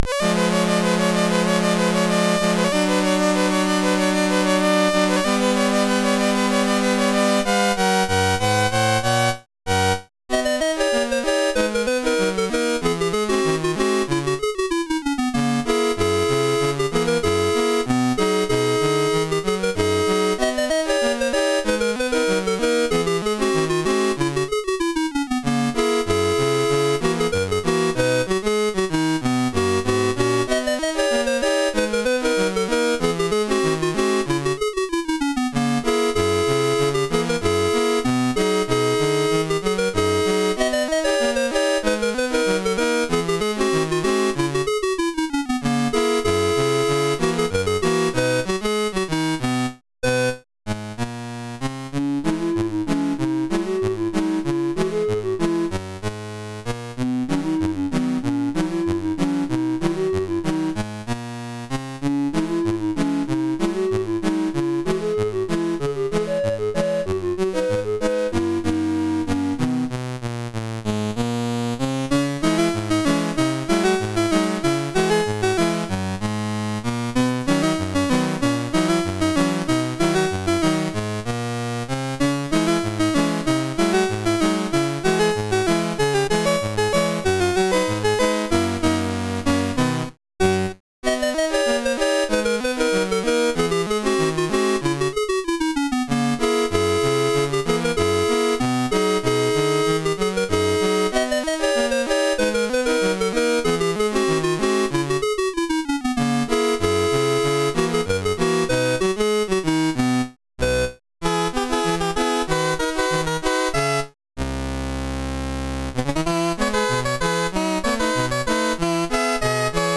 Loadstar 191 home *** CD-ROM | disk | FTP | other *** search / Loadstar 191 / 191.d81 / wildcherries.mus ( .mp3 ) < prev Commodore SID Music File | 2022-08-26 | 2KB | 1 channel | 44,100 sample rate | 3 minutes